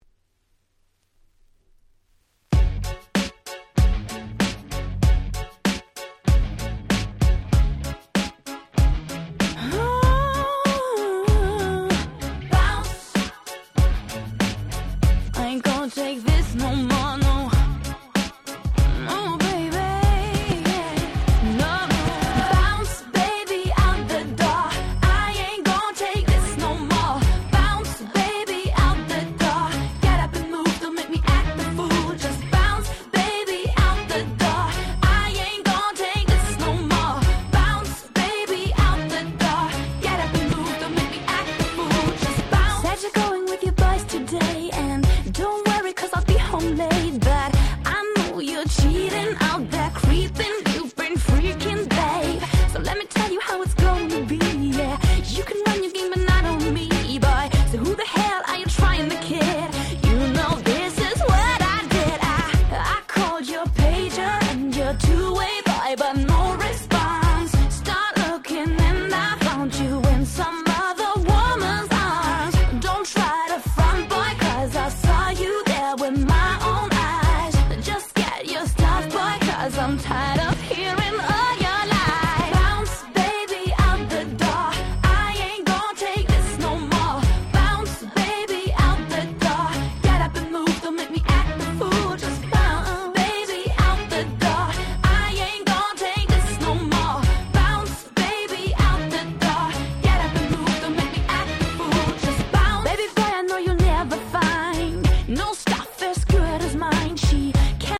03' Nice R&B !!
フロア映えするキャッチーな良曲！